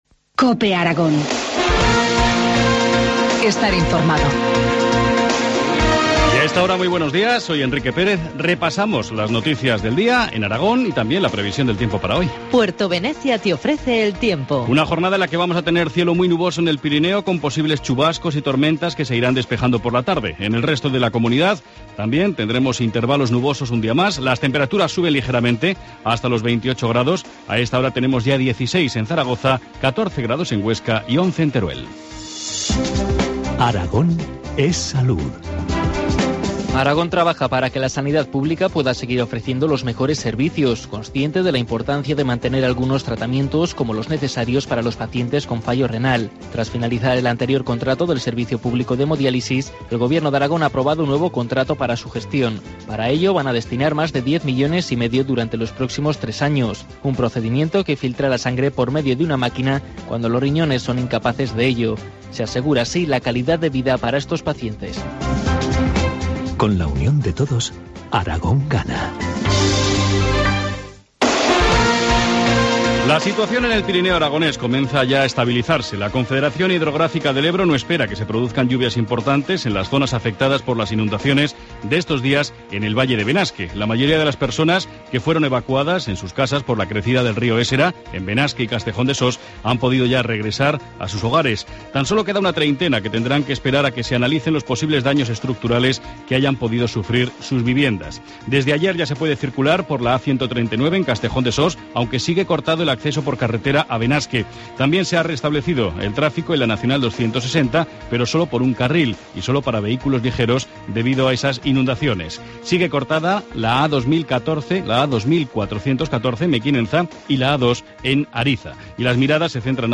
Informativo matinal, jueves 20 de junio, 8.25 horas